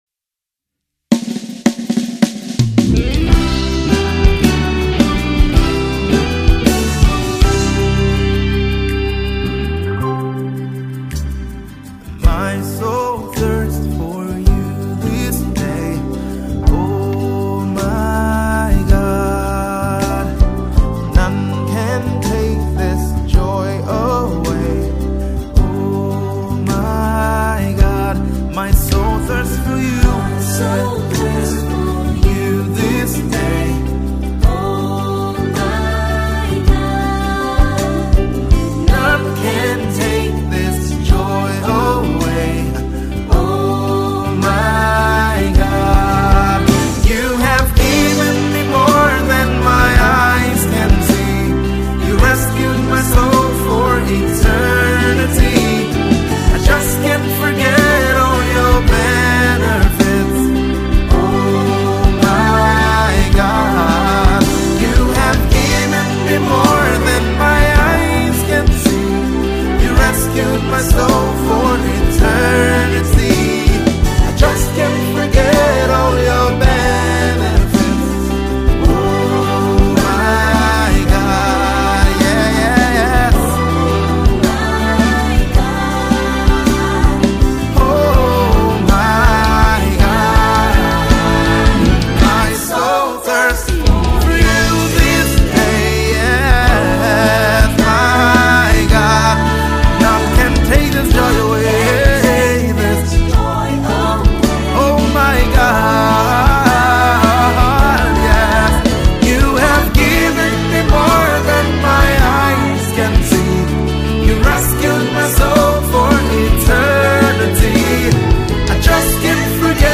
The simple worship song
Guitars
Bass
Drums